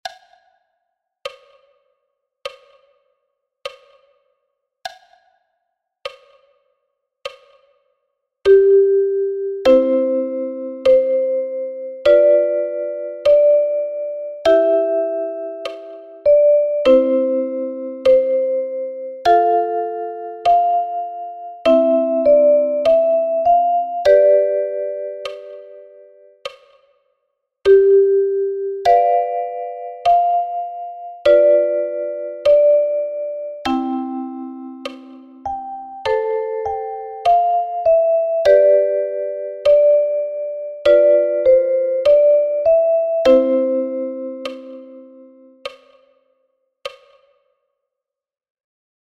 einfach arrangiert für eine/n Kalimba-Spieler/in.